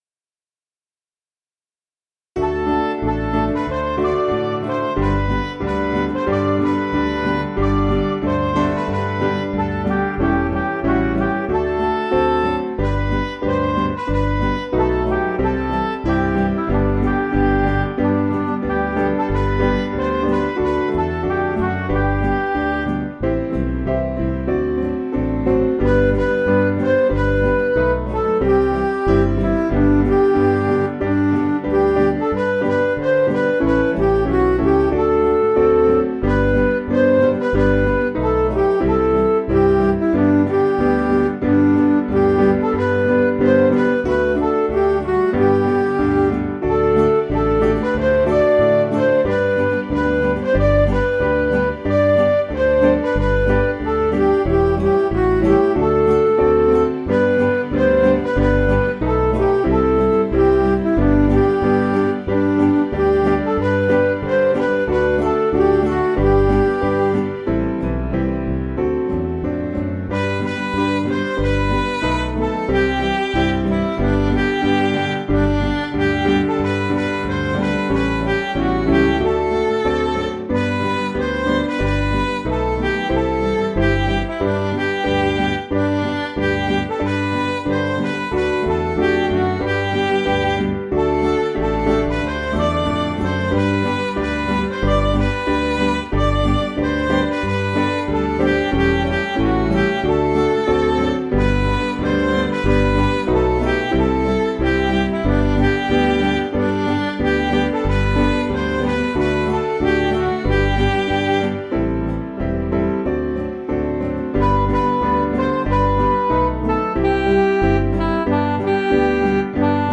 Piano & Instrumental
(CM)   4/G